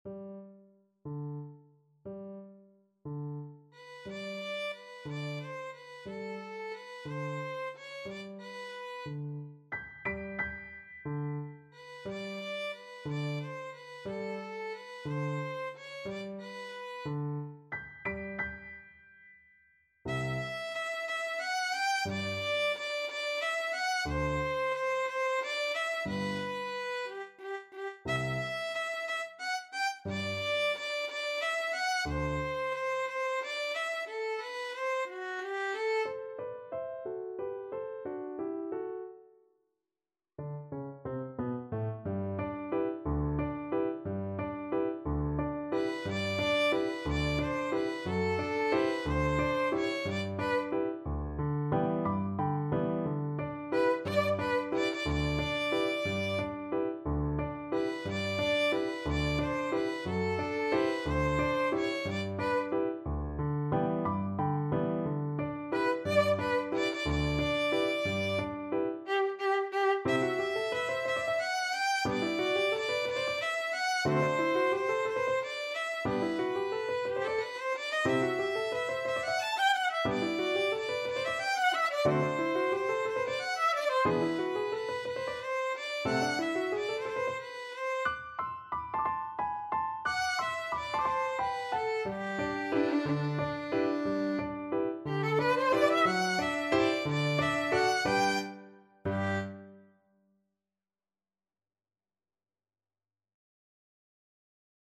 Free Sheet music for Violin
Violin
6/8 (View more 6/8 Music)
G major (Sounding Pitch) (View more G major Music for Violin )
Pochissimo pi mosso = 144 . =60
D5-A6
Classical (View more Classical Violin Music)